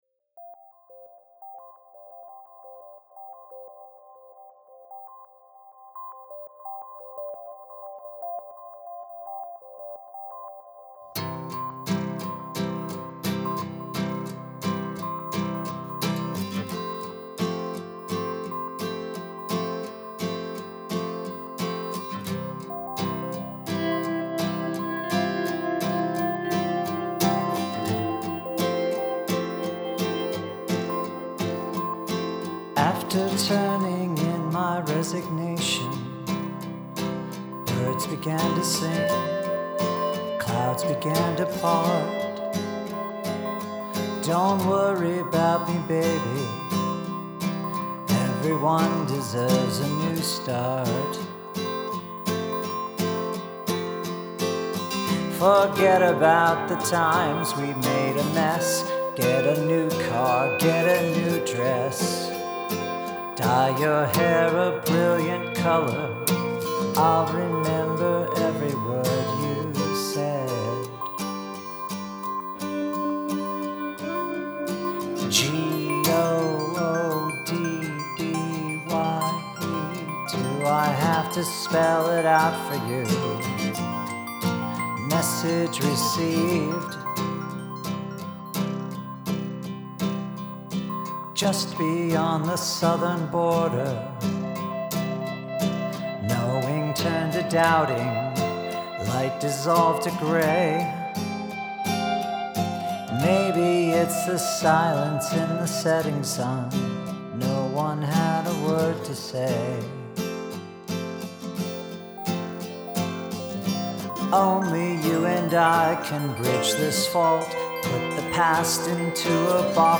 Love the intro arppegiator. Well sung. Melancholic.